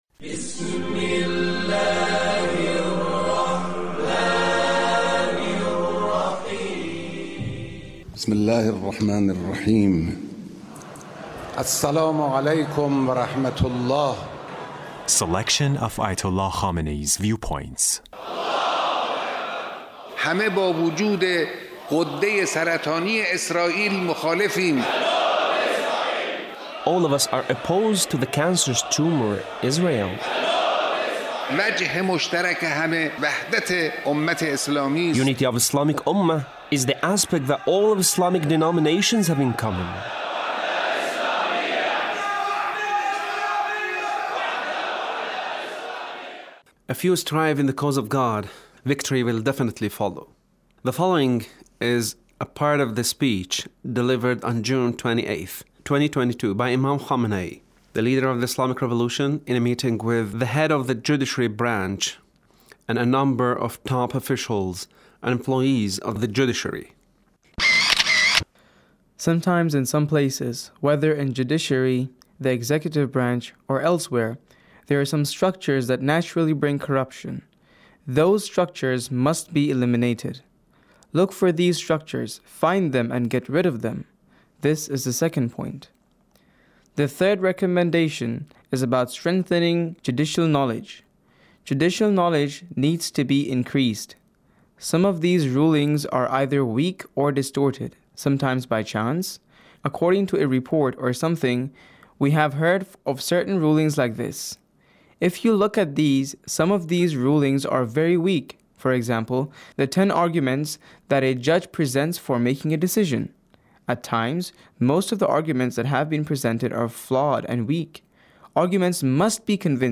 Leader's Speech (1602)
Leader's Speech with Judiciary Officials